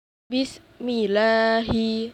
cth lam tarqiq.wav